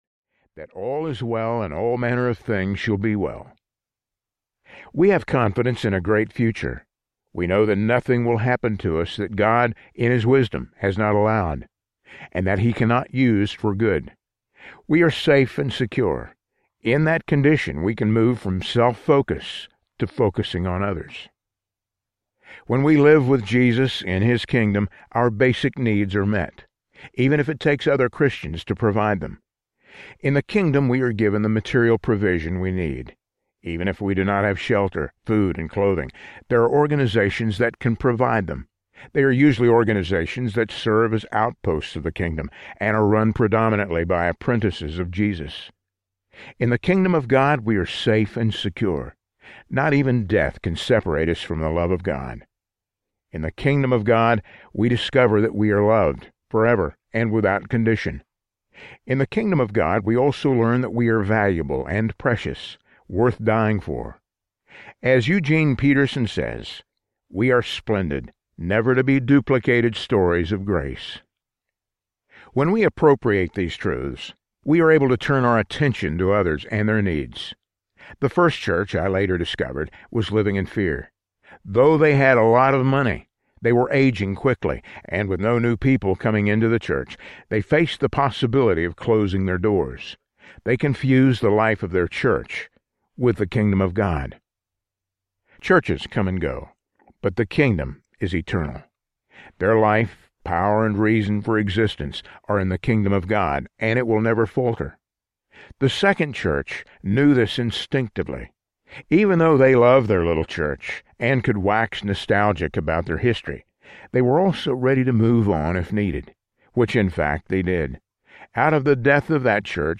The Good and Beautiful Community Audiobook
Narrator
7.25 Hrs. – Unabridged